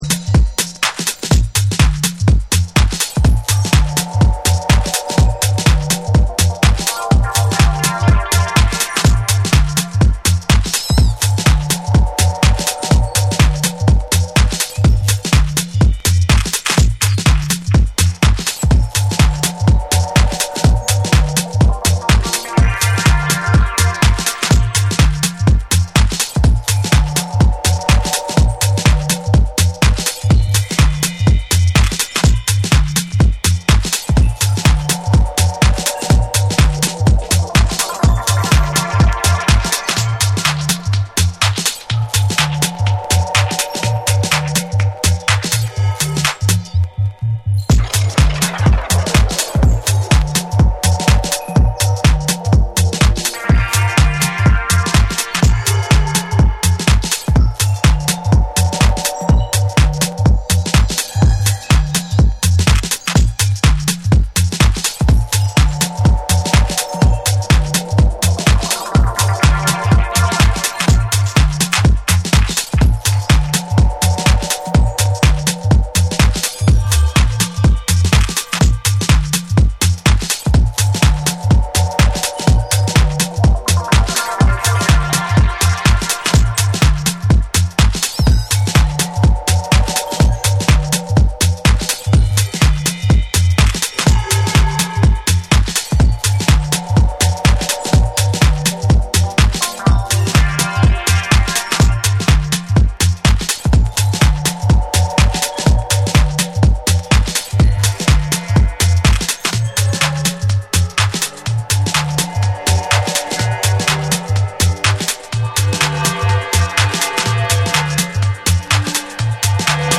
空間を巧みに使ったディープ・グルーヴとミニマルな展開が光るフロア仕様の4トラックを収録。
TECHNO & HOUSE